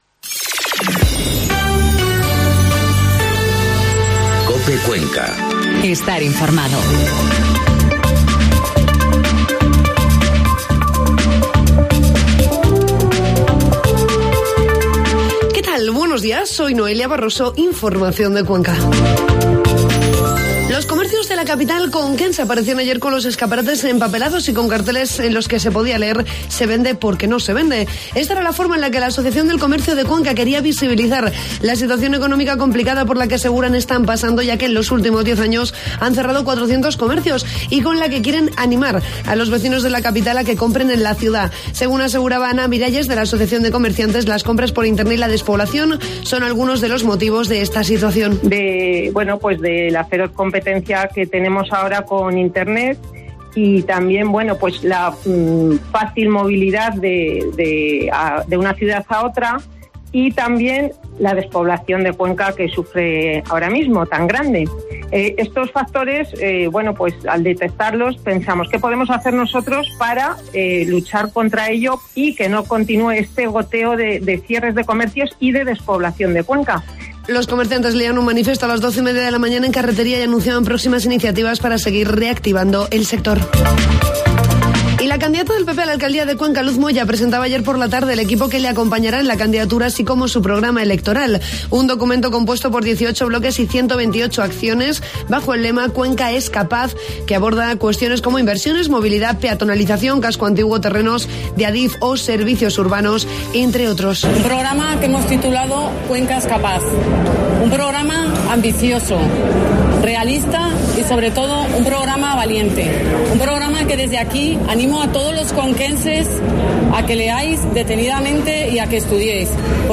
Informativo matinal COPE Cuenca 9 de mayo